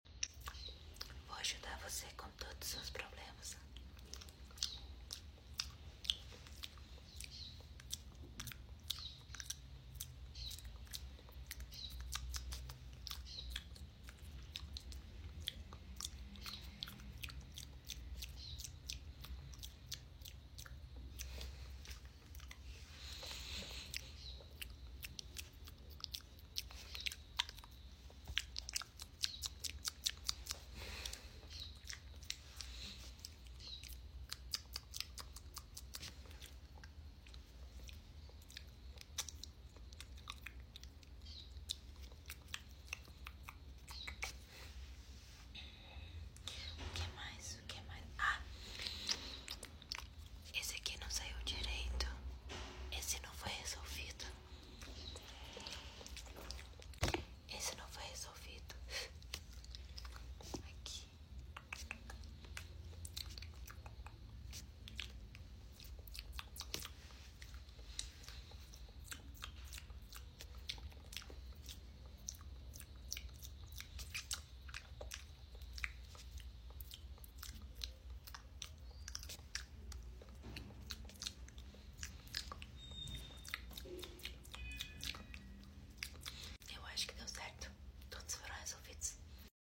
Asmr | Spit Painting 👄 Sound Effects Free Download